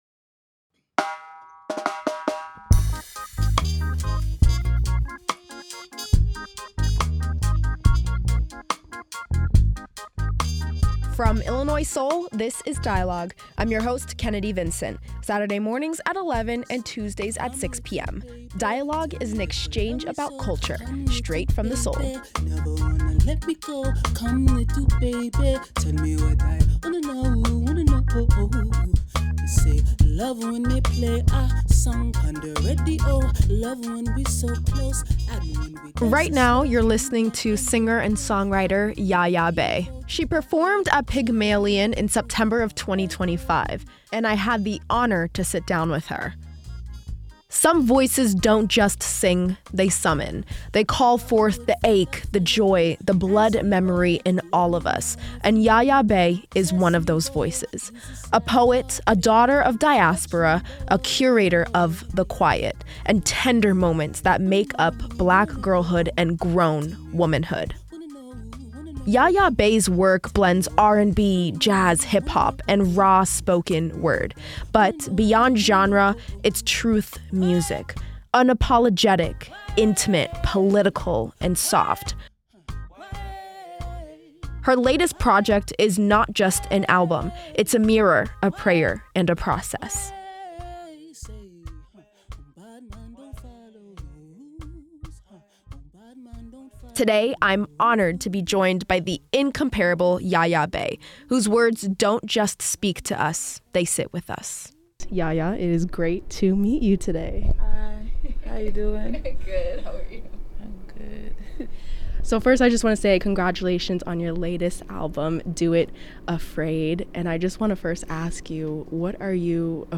R&B artist Yaya Bey talks about her latest album